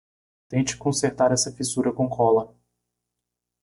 Pronounced as (IPA)
/fiˈsu.ɾɐ/